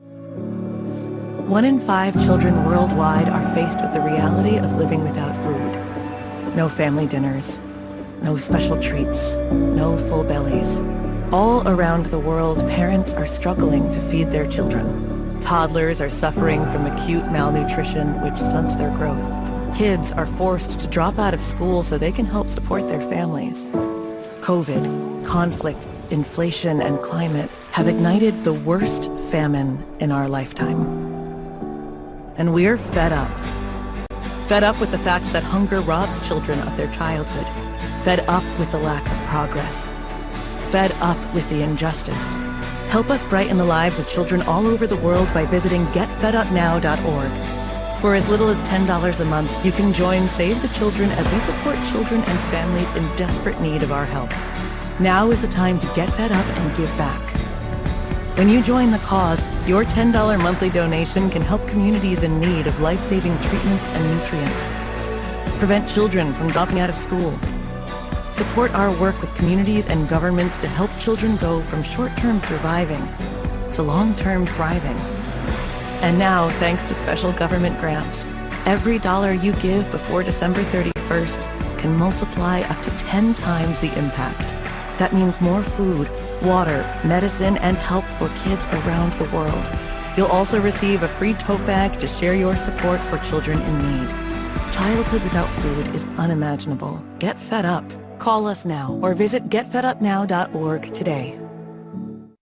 Inglés (Americano)
Natural, Accesible, Maduro, Amable, Cálida
Comercial
▸ Her voice is natural, real, and emotionally present.